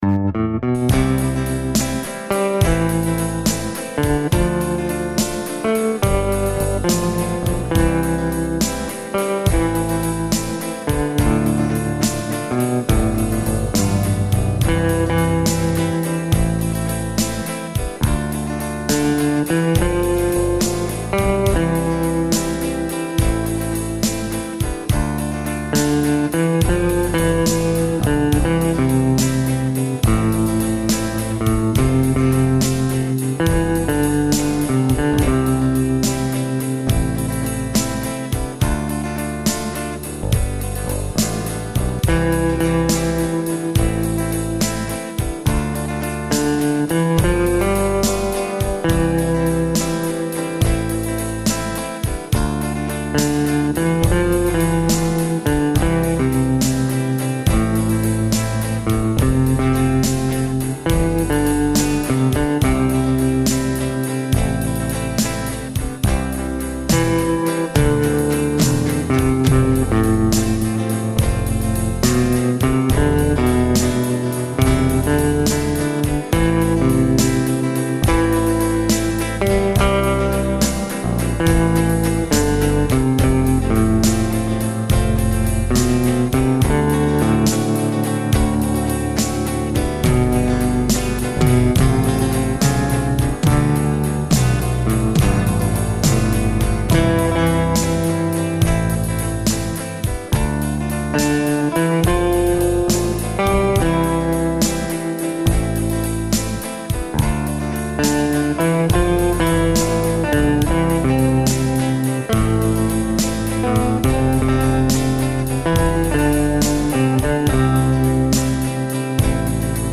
Snímače jsou typu Color Sound od R. M. Pickups, spínače jsou vlastní konstrukce.
Použité materiály, hardware a elektrické zapojení jí dodávají plný zvuk lubové kytary s nepřebernými možnostmi volby barvy.